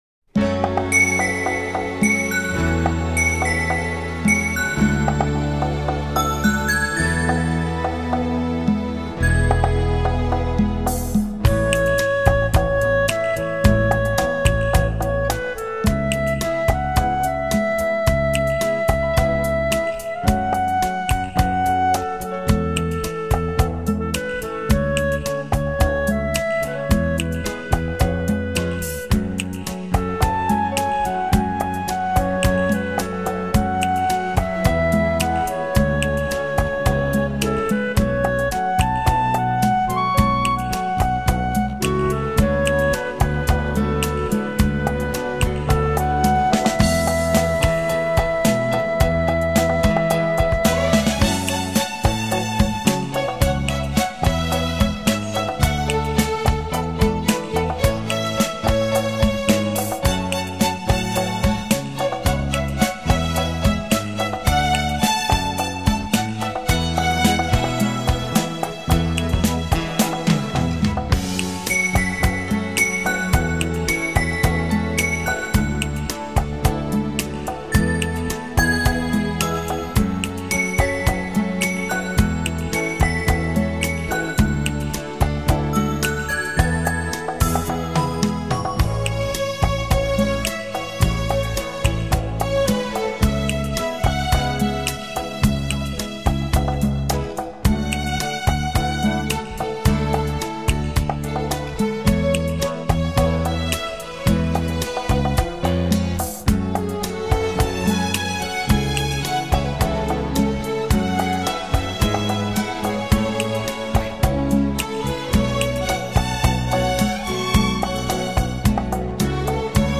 伦巴